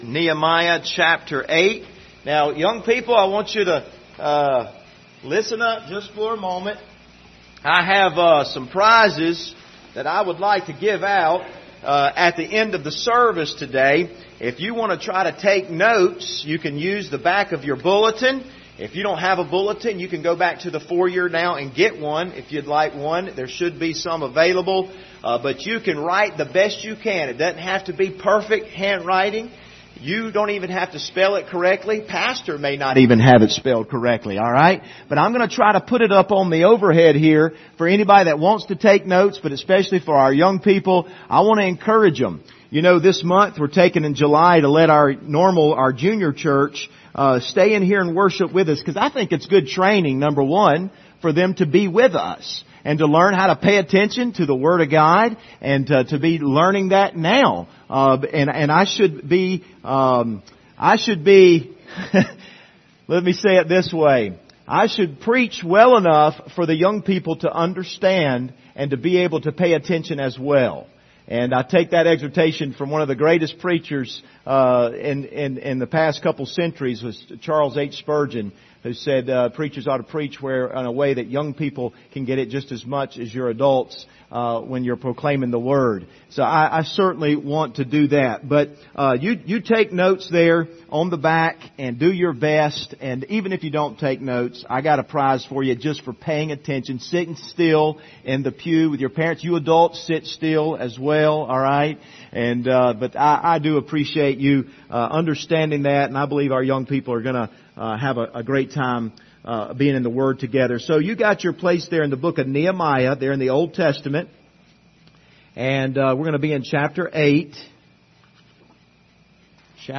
Passage: Nehemiah 8:1-10 Service Type: Sunday Morning